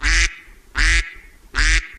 Bebek_Suara.ogg